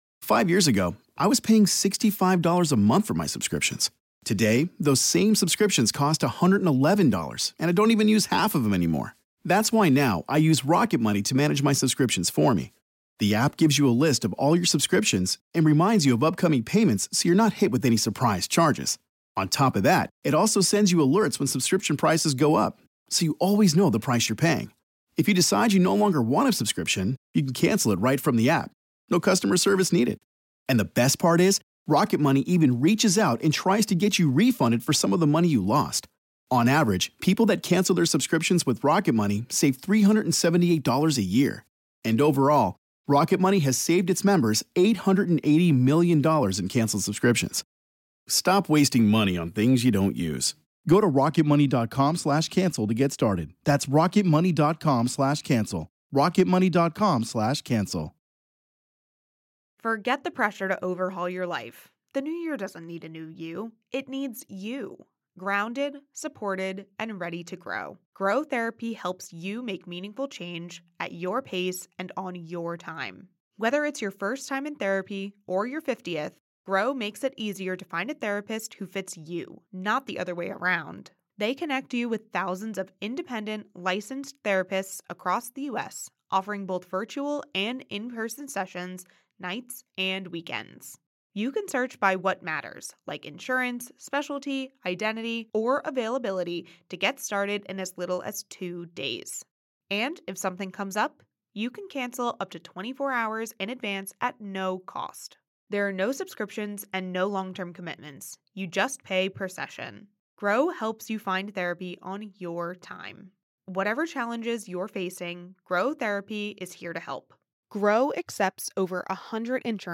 All stories were narrated and produced with the permission of their respective authors.